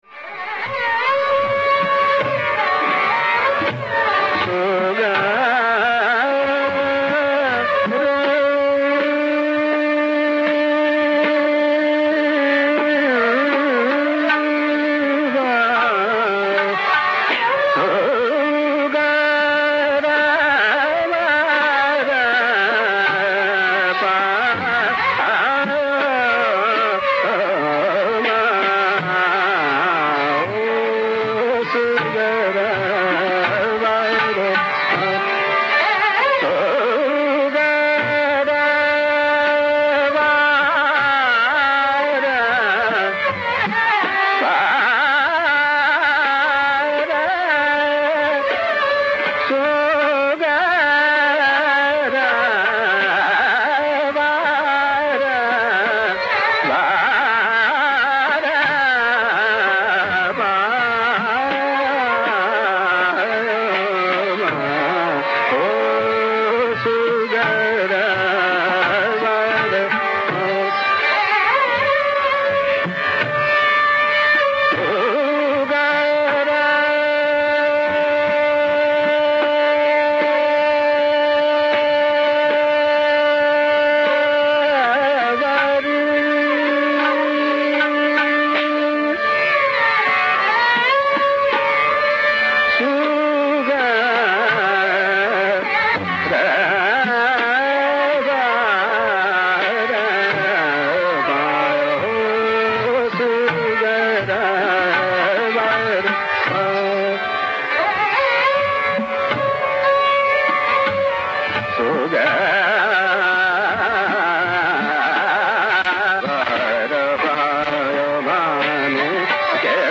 Here we have Husanlal singing it at a Jullandar conference.
Notice here the fleeting but explicit use of shuddha rishab in the tar saptaka (for instance at 0:20).